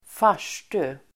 Uttal: [²f'ar_s:tu]